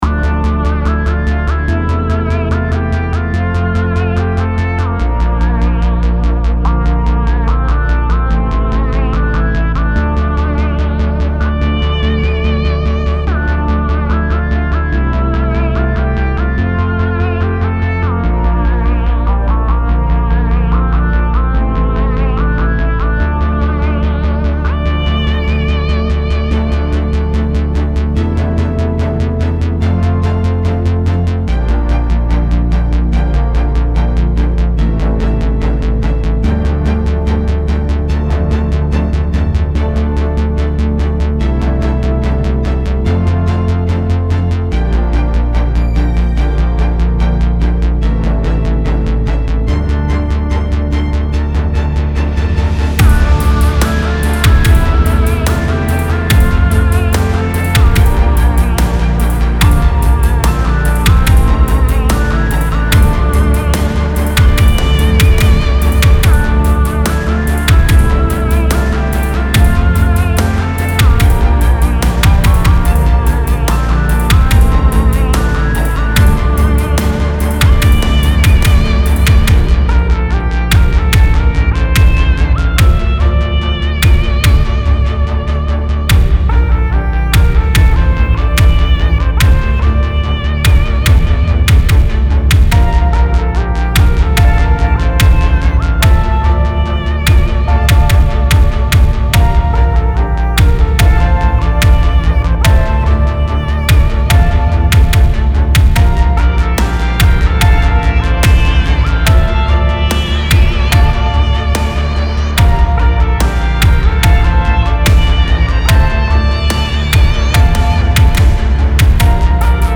Style Style EDM/Electronic, Orchestral, Soundtrack
Mood Mood Cool, Dark, Driving +2 more
Featured Featured Bass, Drums, Strings +1 more
BPM BPM 145